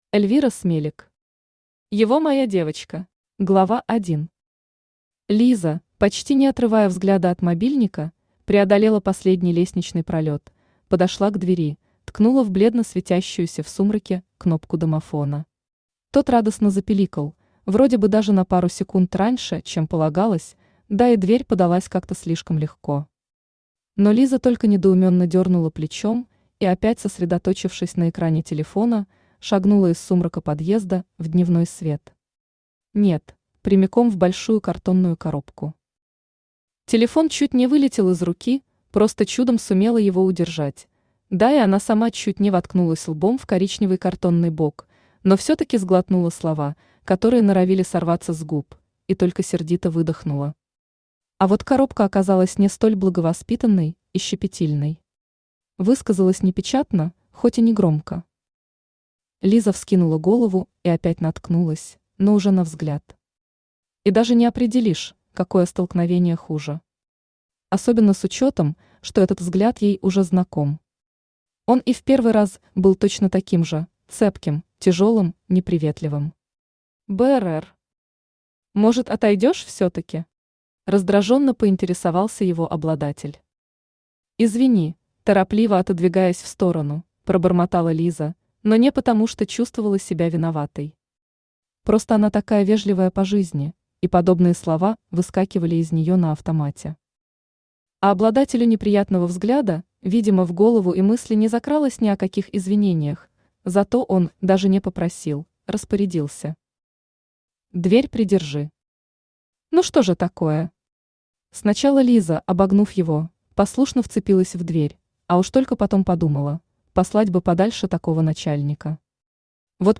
Аудиокнига Его моя девочка | Библиотека аудиокниг
Aудиокнига Его моя девочка Автор Эльвира Смелик Читает аудиокнигу Авточтец ЛитРес.